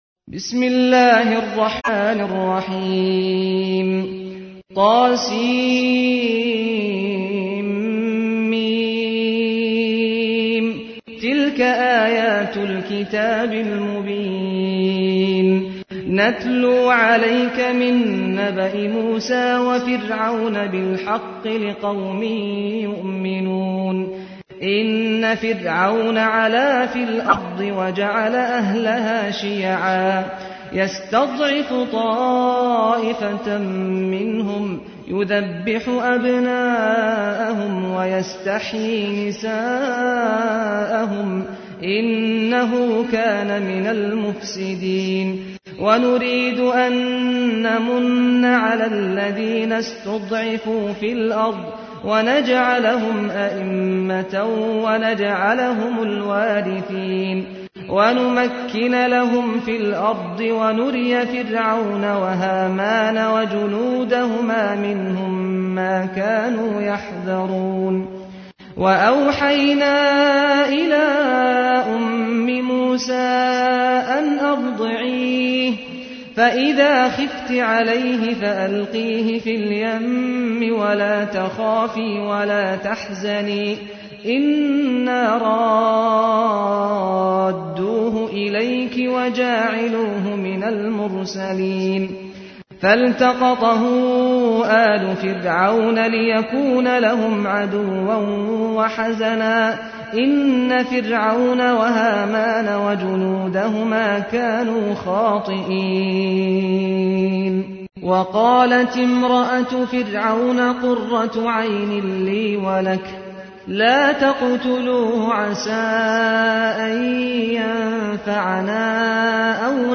تحميل : 28. سورة القصص / القارئ سعد الغامدي / القرآن الكريم / موقع يا حسين